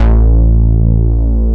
RNDMOOG3.wav